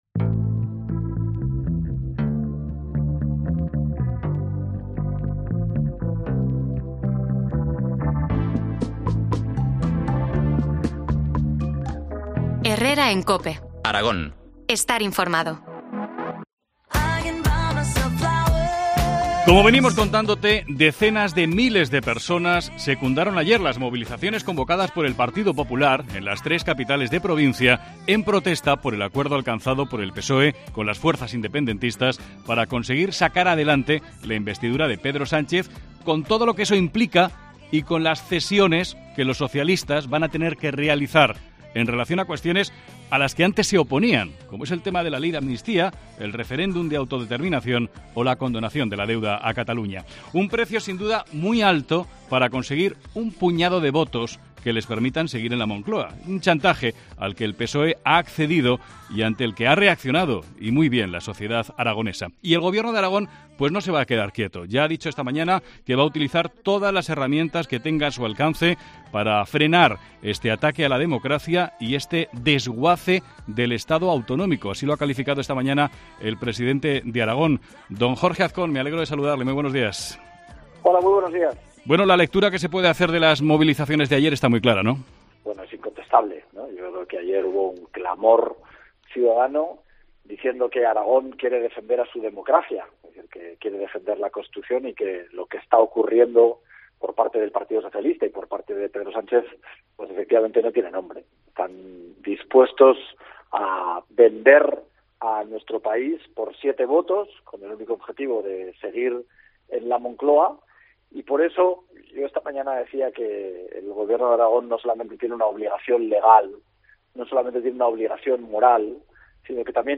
Entrevista al presidente de Aragón, Jorge Azcón.